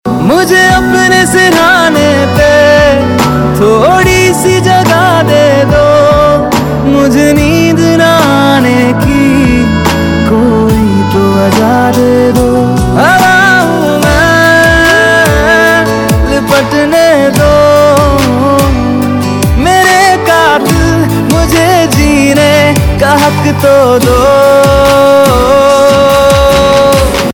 Bollywood - Hindi